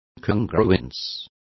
Complete with pronunciation of the translation of congruences.